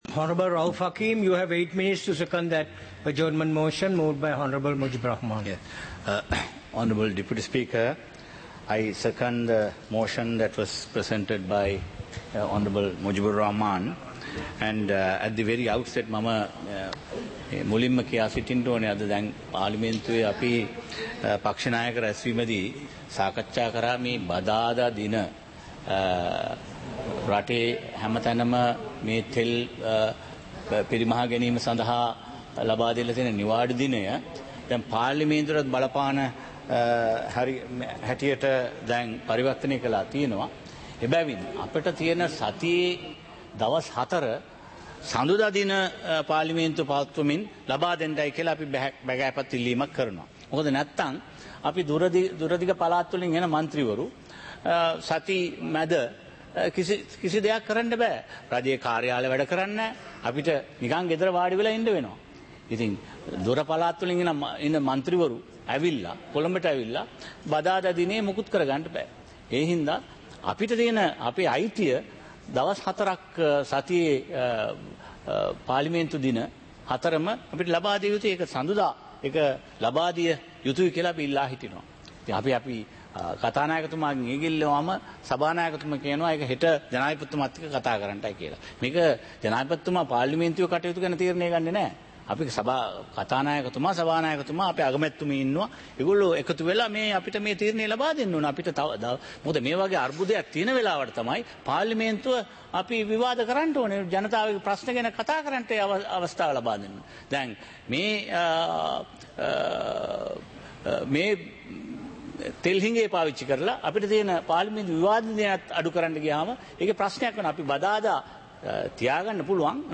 சபை நடவடிக்கைமுறை (2026-03-19)